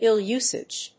アクセントíll‐úsage